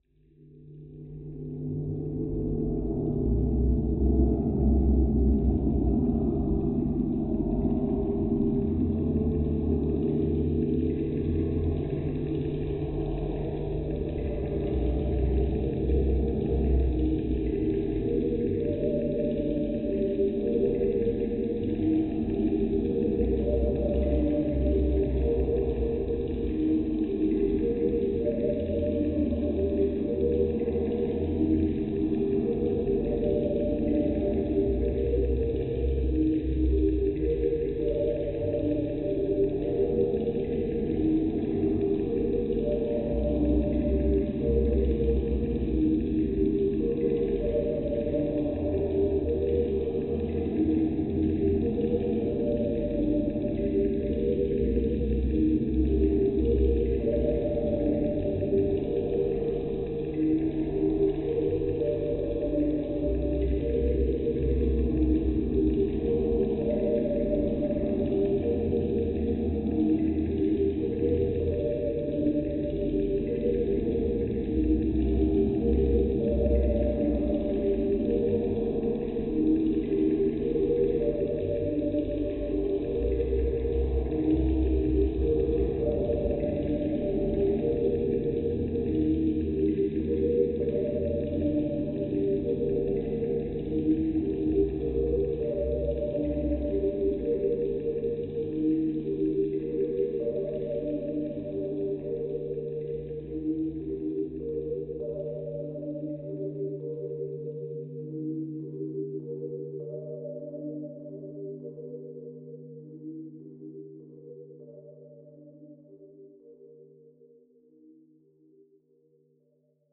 A melodic track perfect for sad and eerie game scenes.